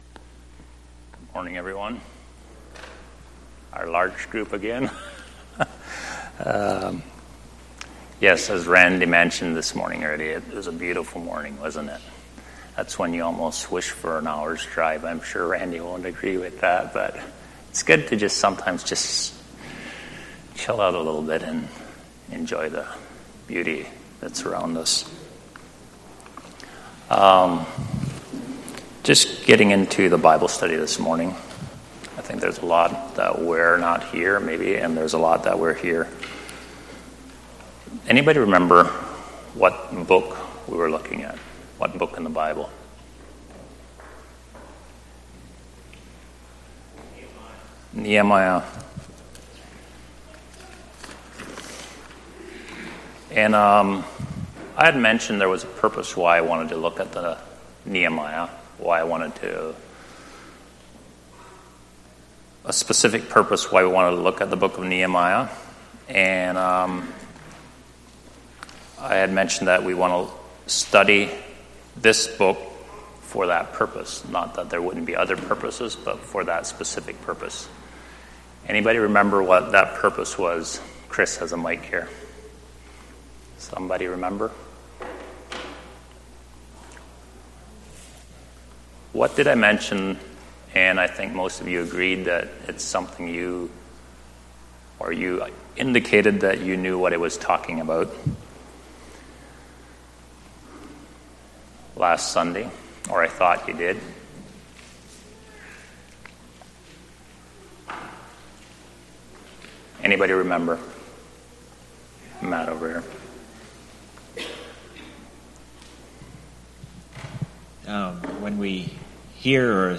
Series: Sunday Morning Bible Study Service Type: Sunday Morning